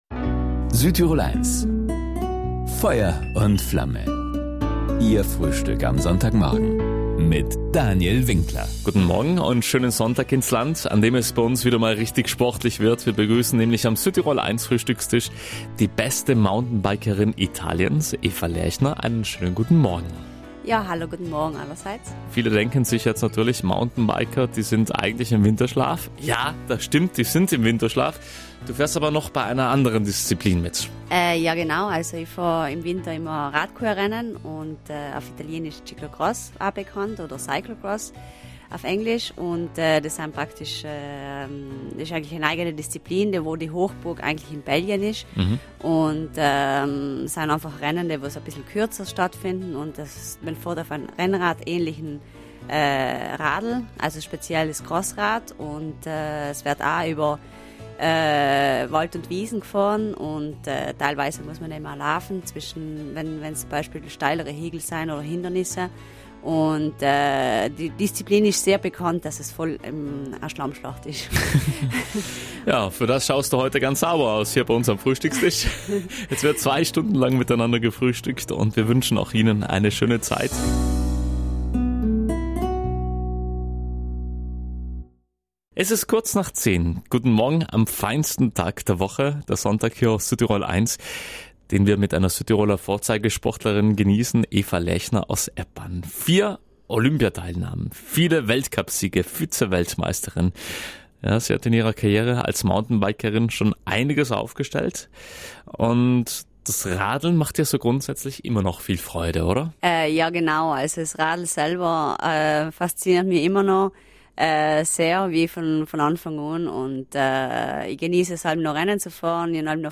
Mittlerweile gehört sie zum „alten Eisen“, wie sie sagt, warum sie trotzdem noch lange nicht ans Aufhören denkt, verriet die sympathische Eppanerin diesmal im Sonntagsfrühstück „Feuer & Flamme“ auf Südtirol 1.